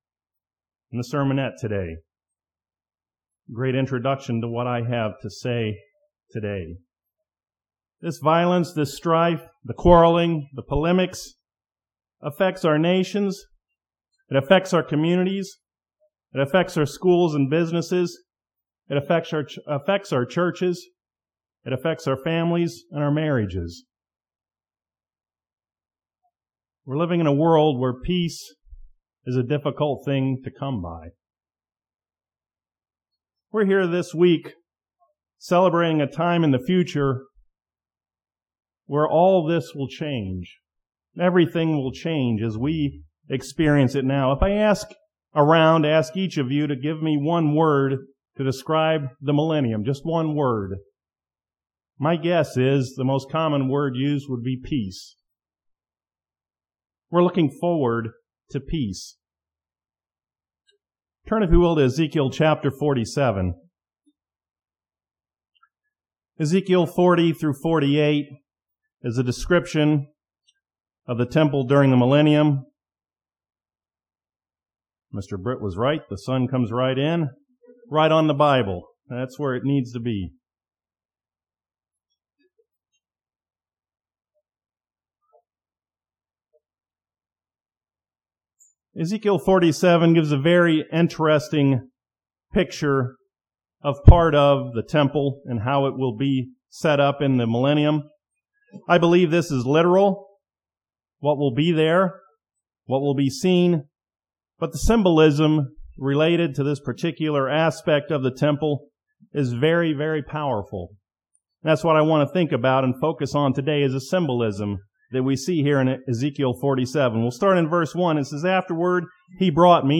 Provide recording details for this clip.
This sermon was given at the Lancaster, Pennsylvania 2020 Feast site.